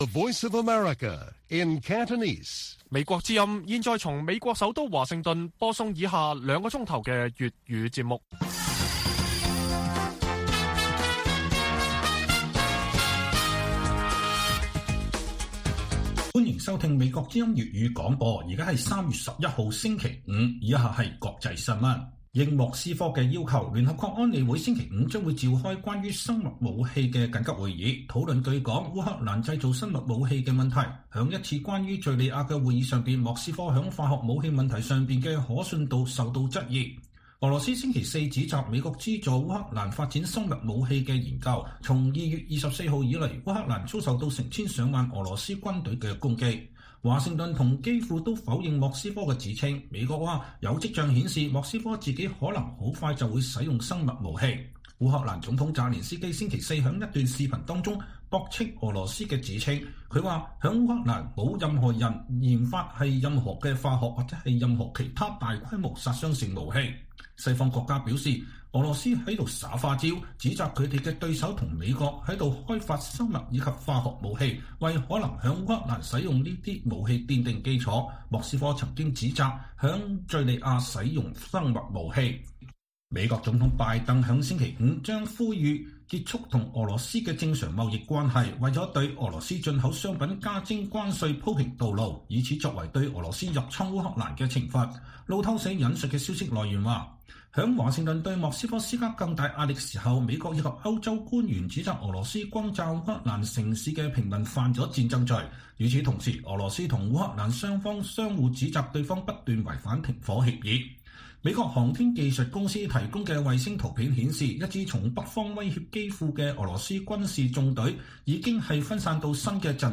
粵語新聞 晚上9-10點 : 南韓下任總統尹錫悅是否會真正對華強硬？